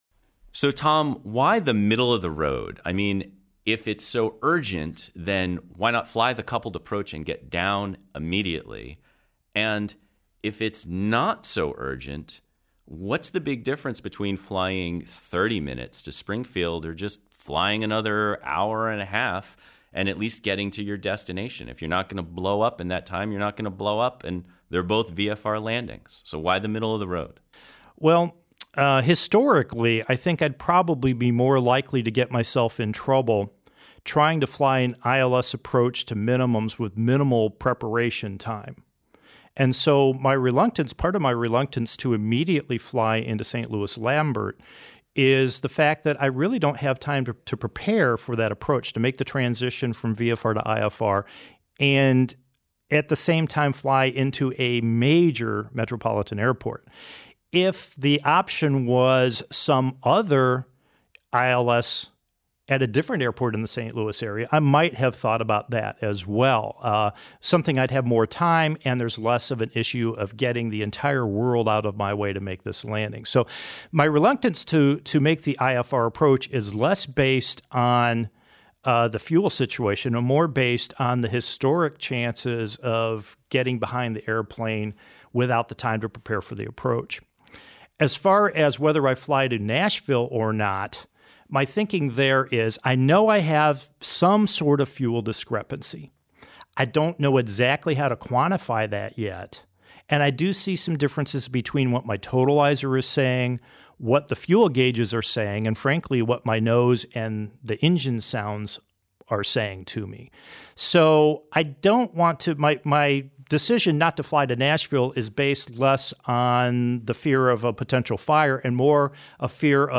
Aromatic Issues Over St. Louis_Roundtable.mp3 V3.mp3